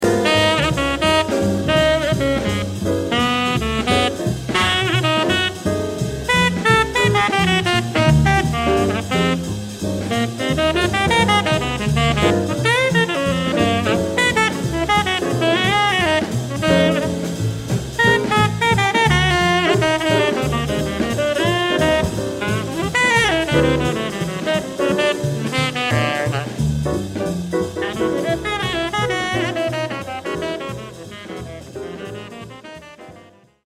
A good feature for drums with lots of rhythm section hits.
It's three choruses by one of the masters of the tenor sax.